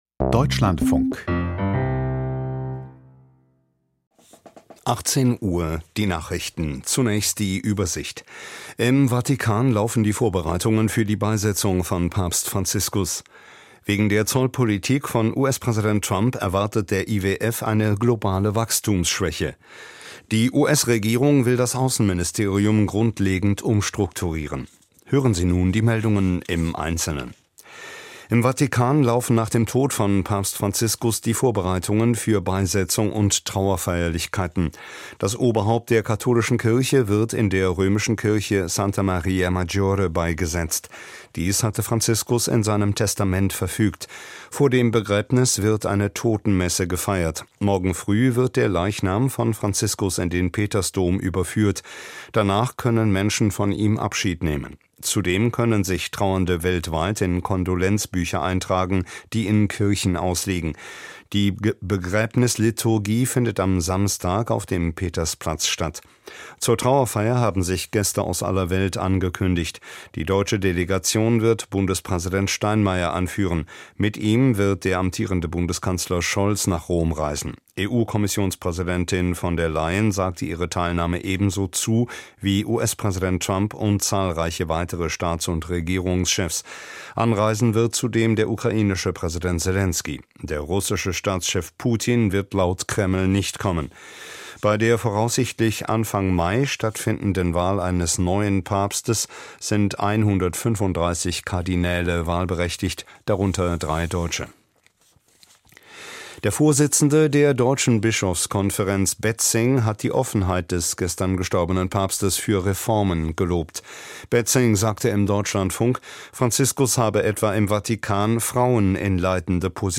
Die Nachrichten
News aus der Deutschlandfunk-Nachrichtenredaktion.